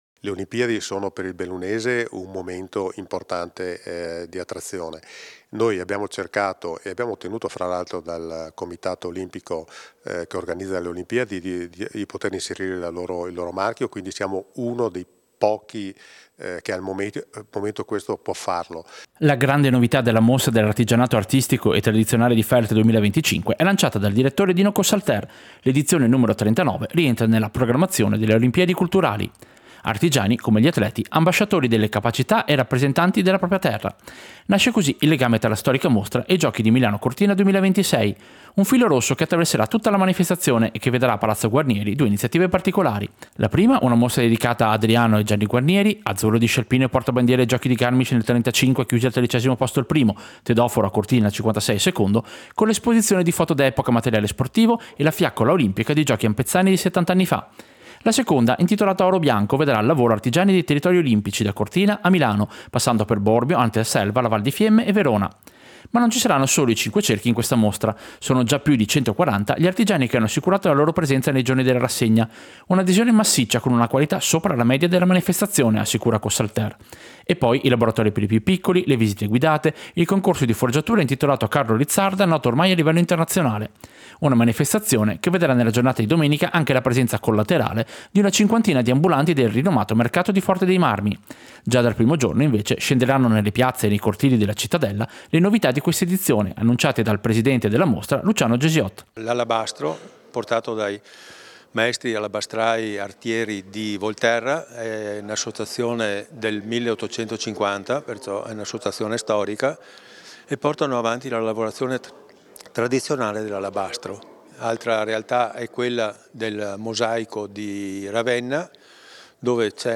Servizio-presentazione-Mostra-Artigianato-Feltre-2025.mp3